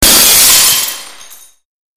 glasssmash.mp3